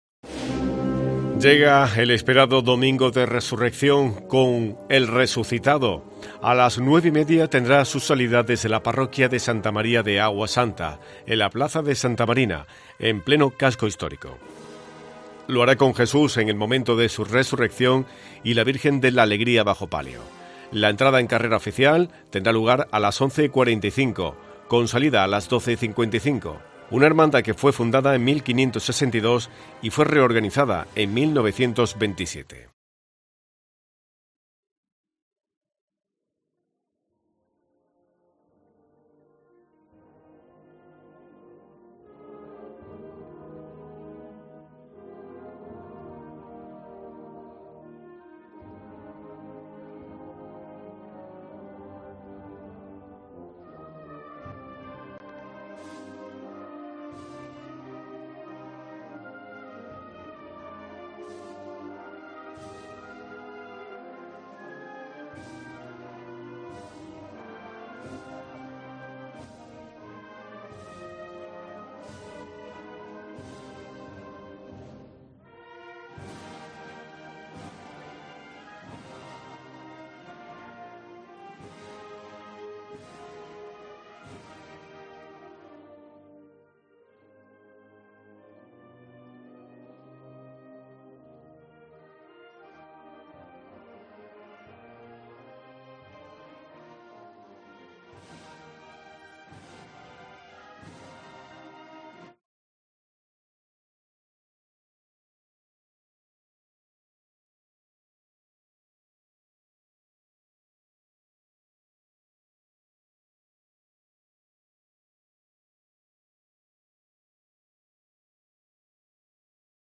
Las campanas en Córdoba nos recuerdan que Jesús ha resucitado